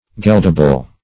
Search Result for " geldable" : The Collaborative International Dictionary of English v.0.48: Geldable \Geld"a*ble\, a. Capable of being gelded.